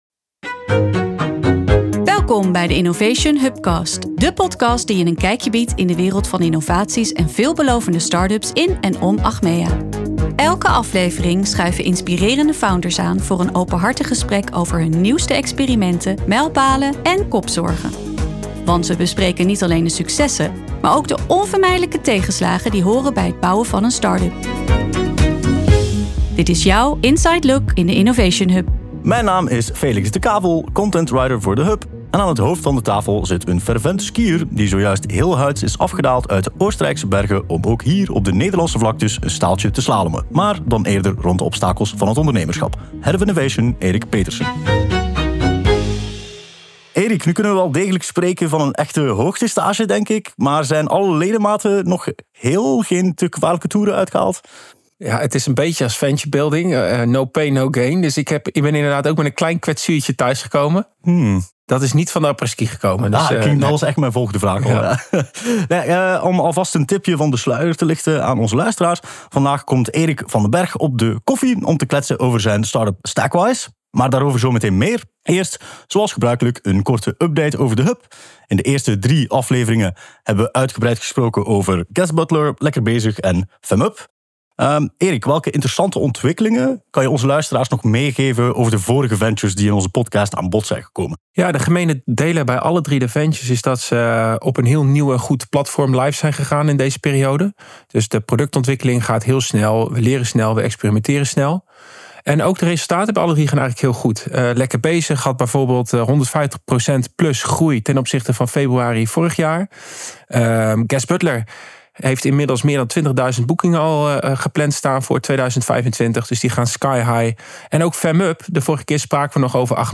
De Innovation Hubcast geeft je een exclusief kijkje in de wereld van baanbrekende innovaties en veelbelovende startups in en om Achmea. Elke aflevering schuiven inspirerende founders aan voor een open gesprek over hun nieuwste experimenten, inzichten en ideeën.